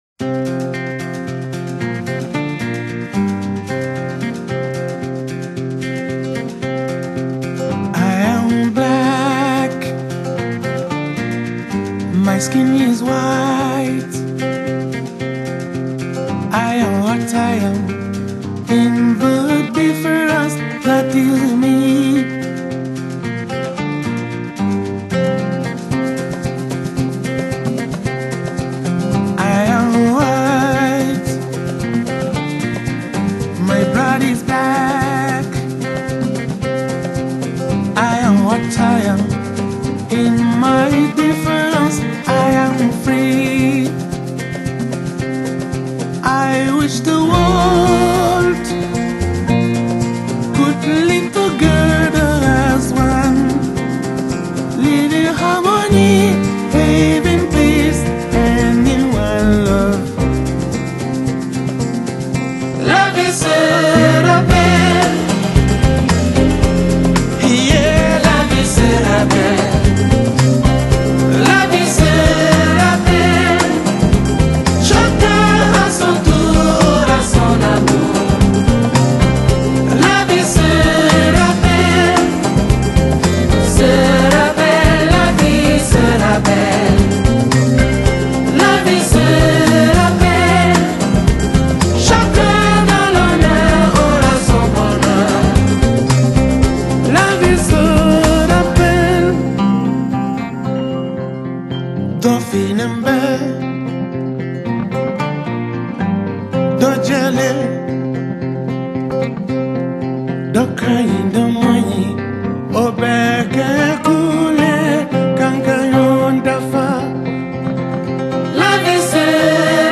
Genre: West African, Malian Music, African Traditions